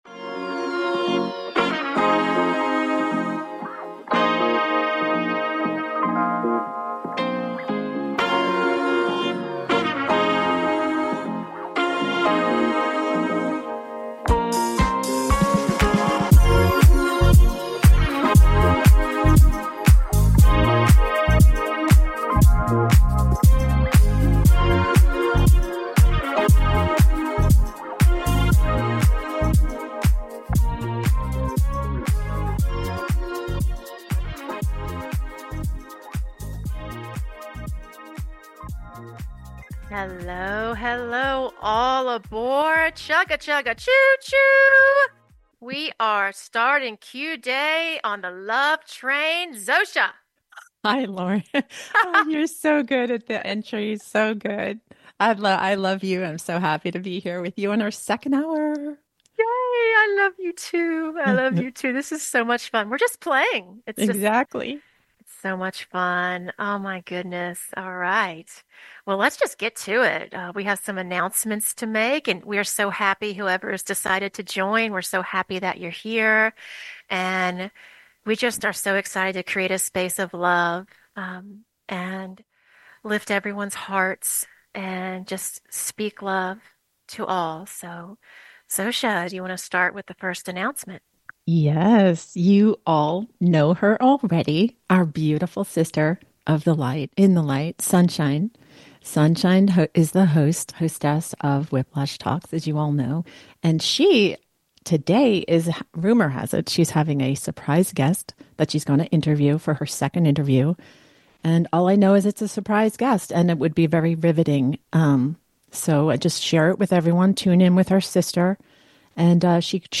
Talk Show Episode, Audio Podcast, UNSTOPPABLE LOVE and OUR NEW 2ND HOUR!